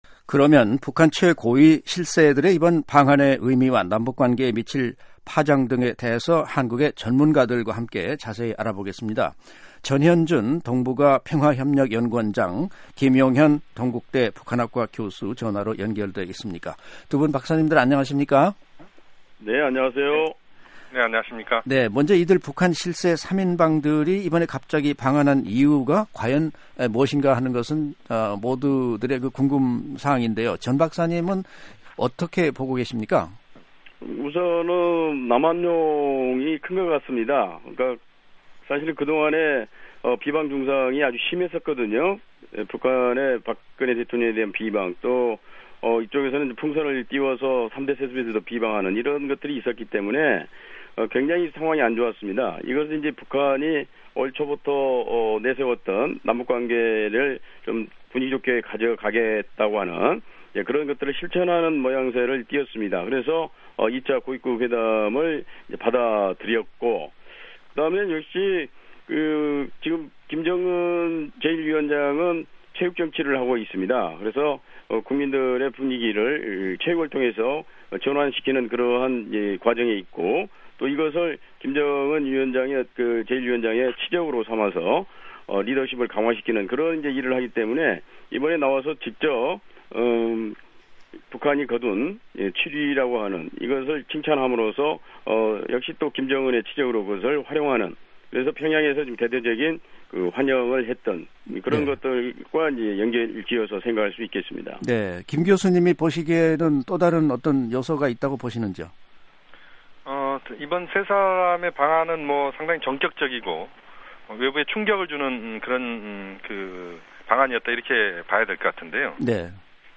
[전문가 인터뷰] 북한 '실세 3인방' 전격 방한 의미와 파장